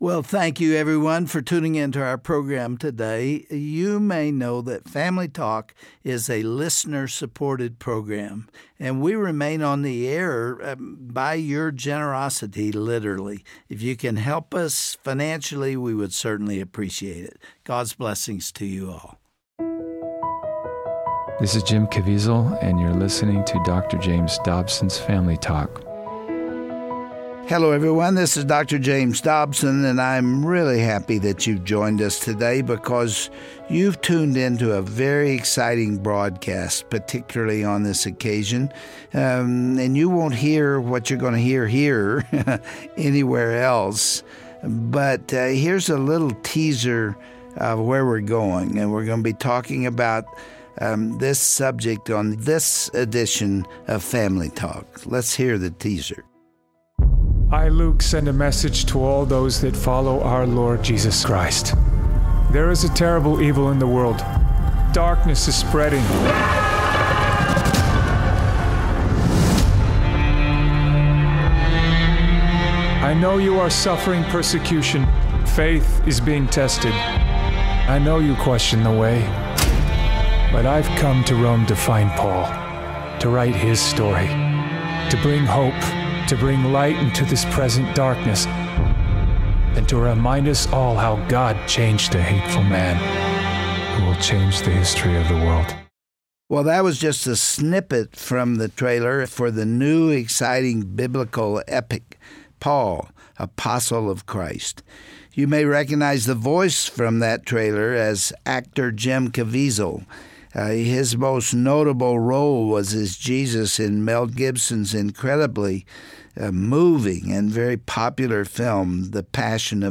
Today Family Talk is excited to present an exclusive interview with actor Jim Caviezel, who stars as the Apostle Luke in the new movie Paul, Apostle of Christ.